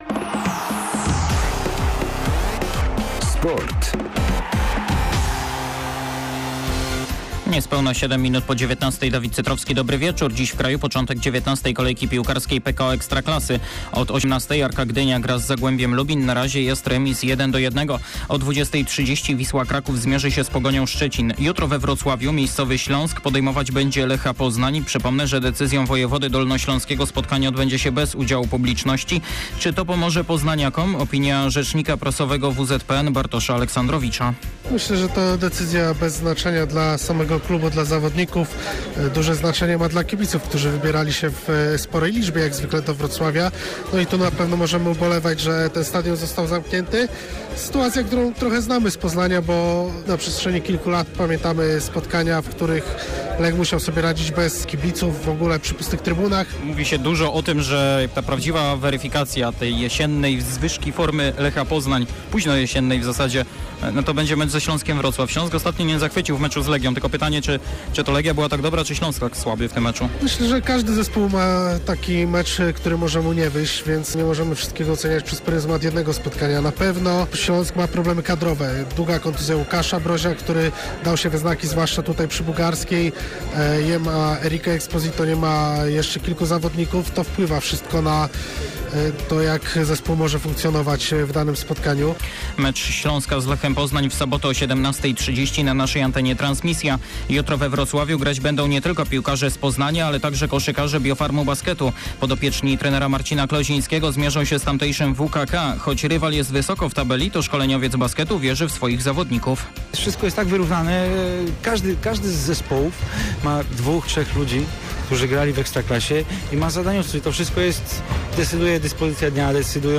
13.12. SERWIS SPORTOWY GODZ. 19:05